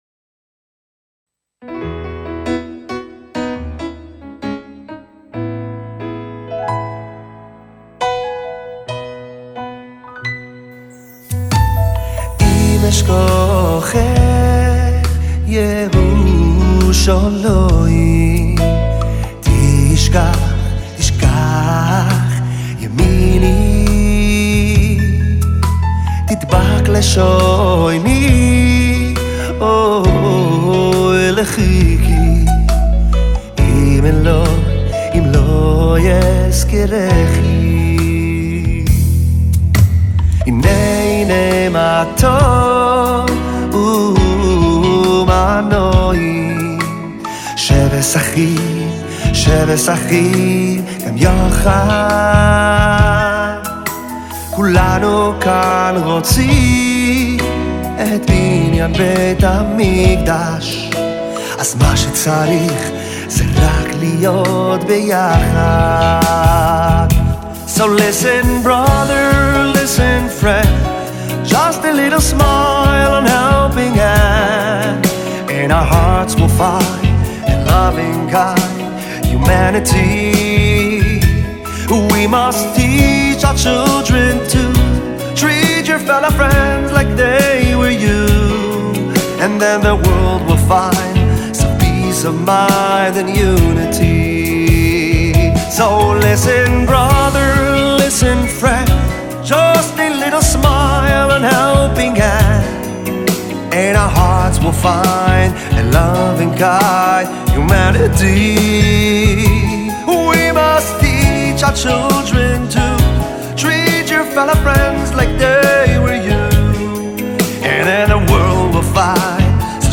דואט סוחף
הזמרים